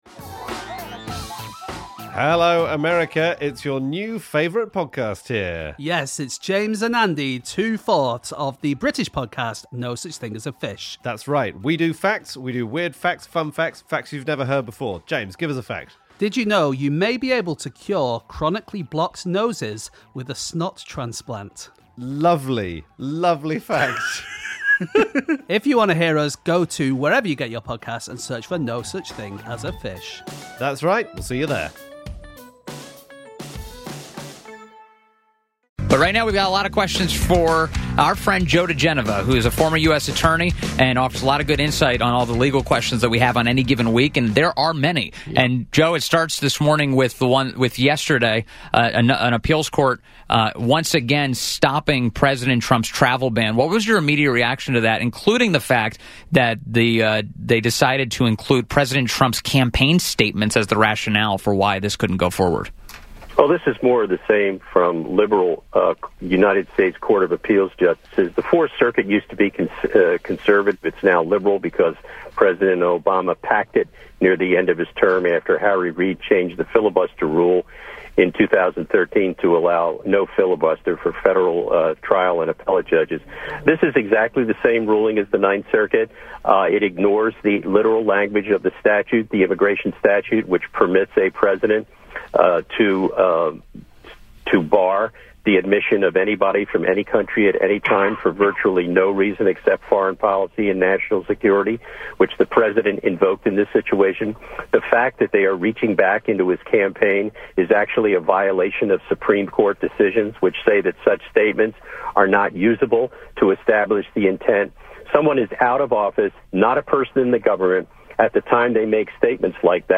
WMAL Interview Joe DiGenova. 05.26.17
FRIDAY 5/26/17 Joe DiGenova, Legal Analyst & Fmr. U.S. Attorney to the District of Columbia.